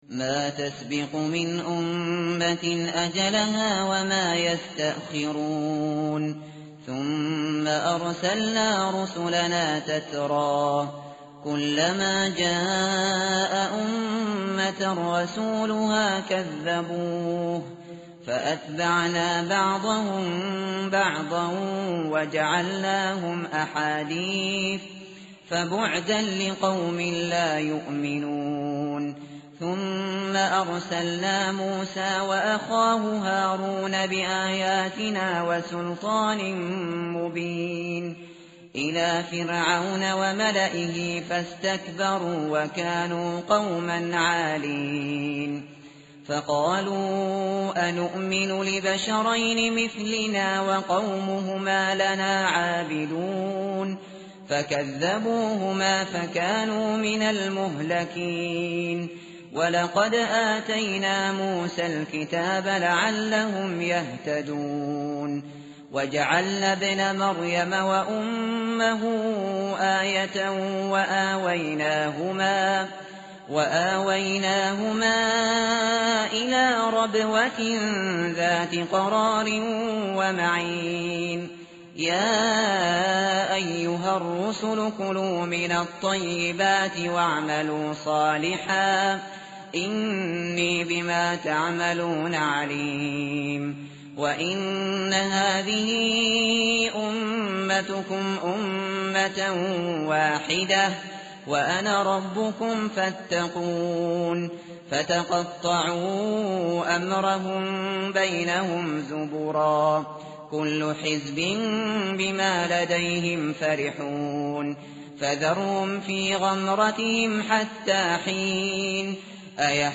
tartil_shateri_page_345.mp3